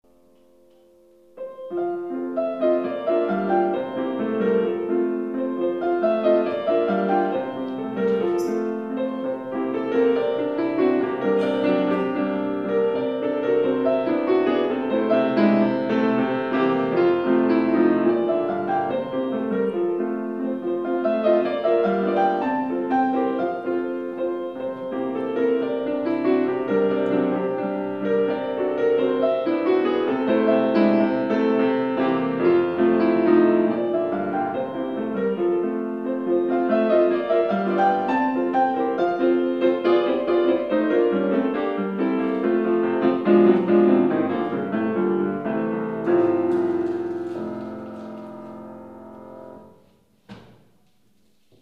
Here's some of my piano recordings.